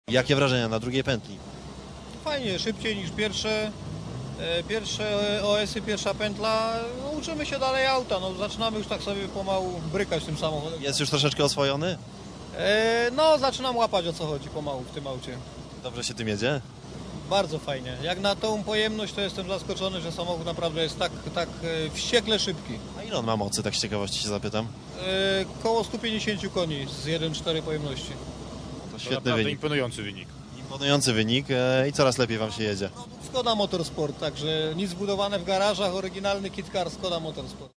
wypowiedź - 33 Rajd Elmot - serwis 2